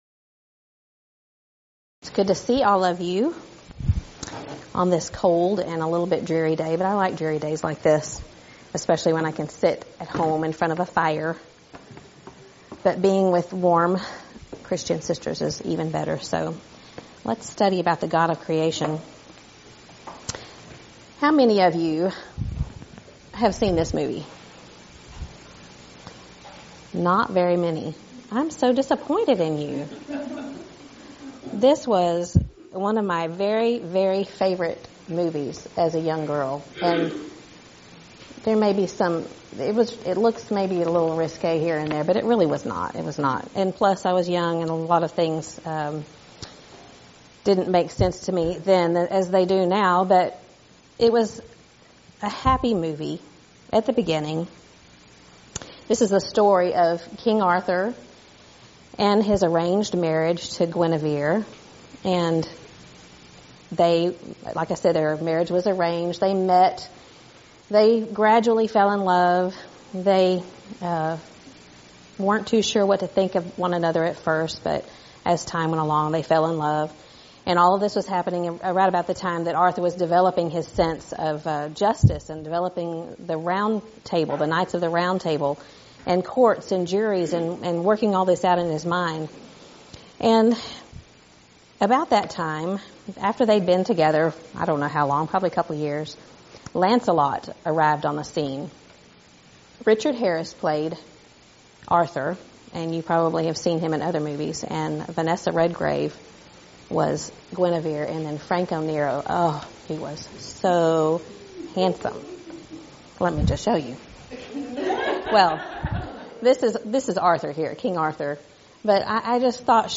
Event: 16th Annual Schertz Lectures Theme/Title: Studies in Genesis
Ladies Sessions